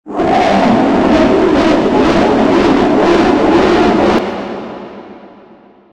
tsm_laugh.ogg